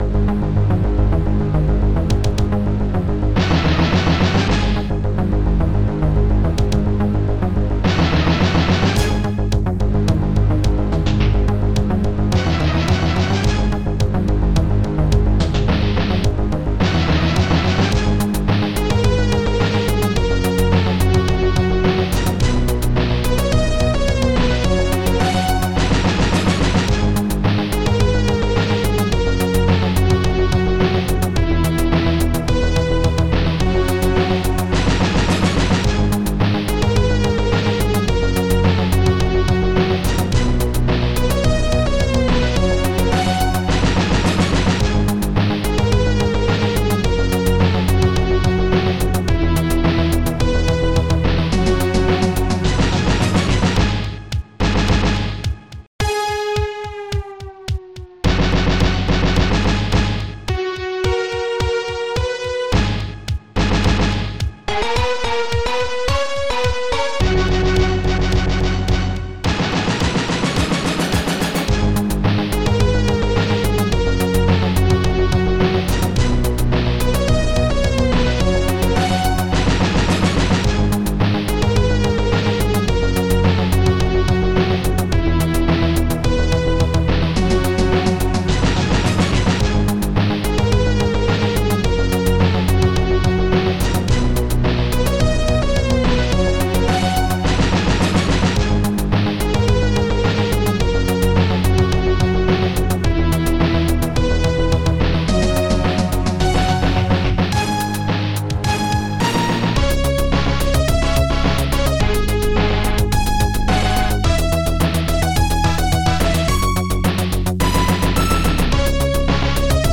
st-01:SNARE123
st-01:ANIMATE-BASS
st-01:orch.hit
st-01:trumpets